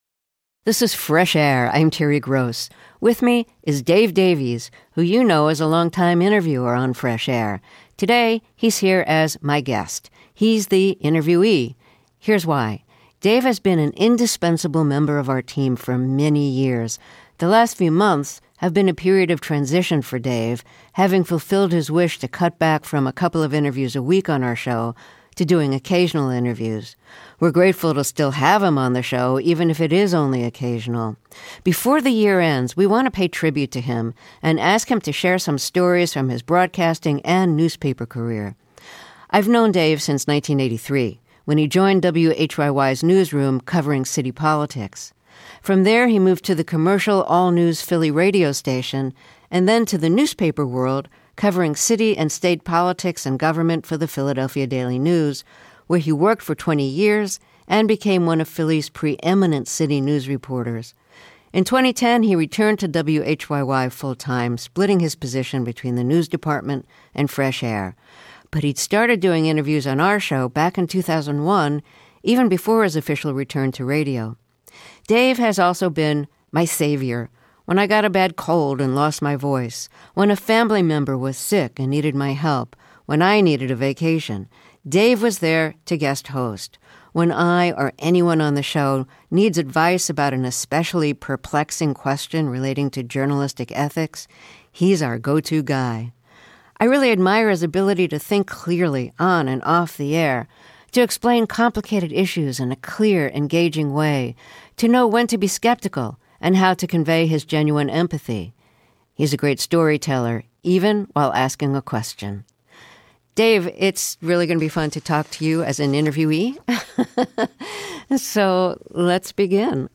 After 22 years with the show, Davies is cutting back on his workload. He chats with Terry Gross about some of his most memorable interviews, and the preparation that goes into each conversation.